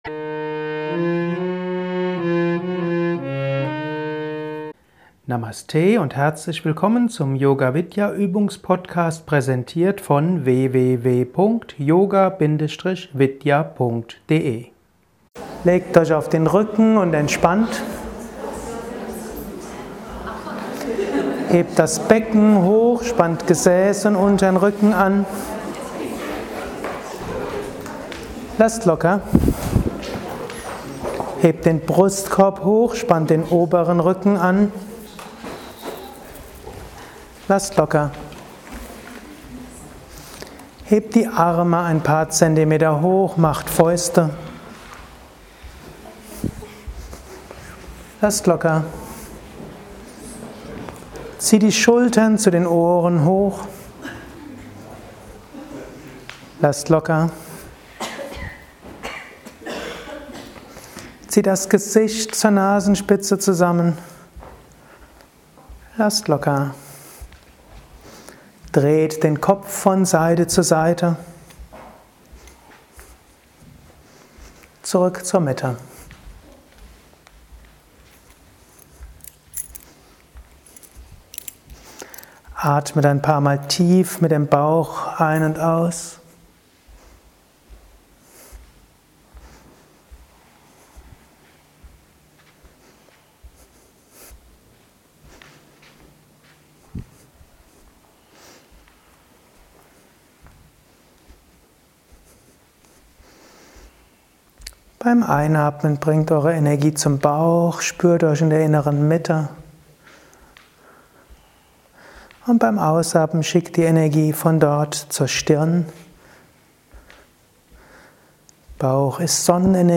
Eine 50-minütige Yogastunde mit verschiedenen sanften Übungen.
Die Übungen werden nicht genauer erläutert - es ist nämlich ein Mitschnitt aus einem Workshop, in dem viel vorgemacht wurde.
Vorsicht: Diese Yogastunde ist zwar sanft, aber nicht für Anfänger geeignet. Sie ist ein Mitschnitt aus einem Workshop aus einer Yogalehrer Weiterbildung bei Yoga Vidya Bad Meinberg.